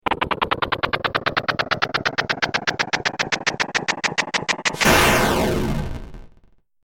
جلوه های صوتی
دانلود صدای بمب 12 از ساعد نیوز با لینک مستقیم و کیفیت بالا